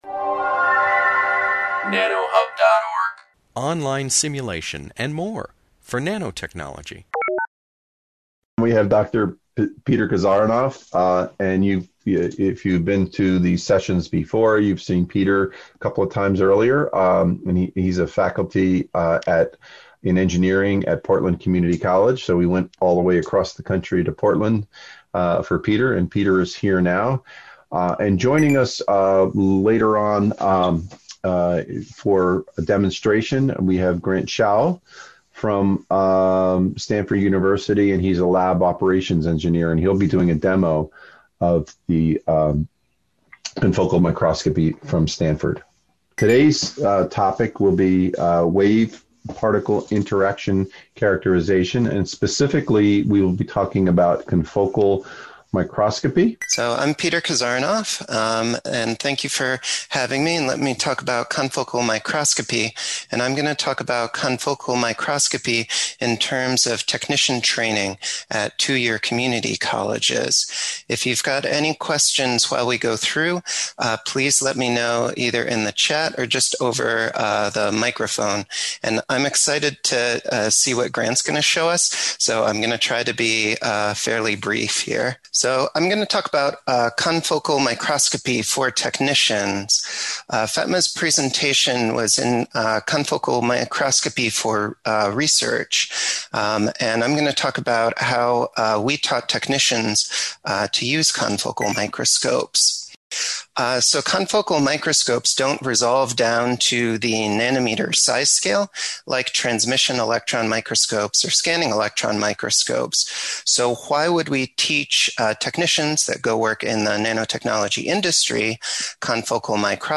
This webinar, published by the Nanotechnology Applications and Career Knowledge Support (NACK) Center at Pennsylvania State University, covers confocal microscopy education for technicians.